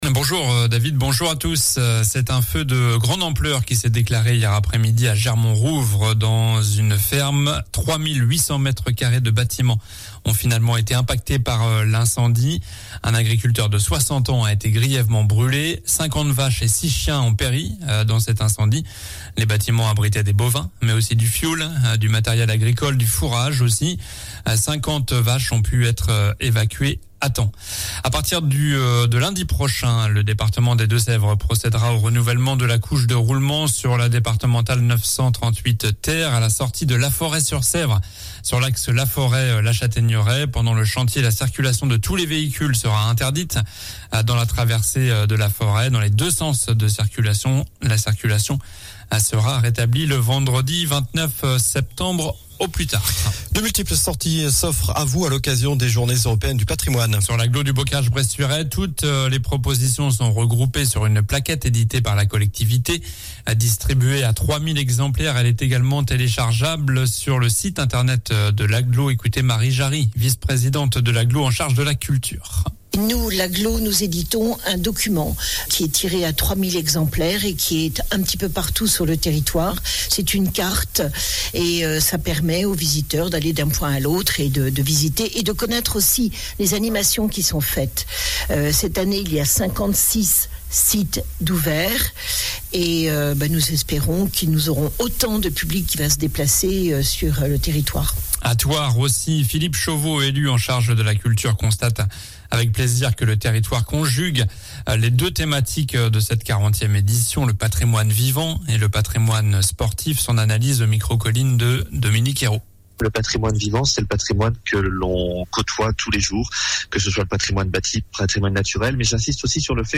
Journal du samedi 16 septembre (matin)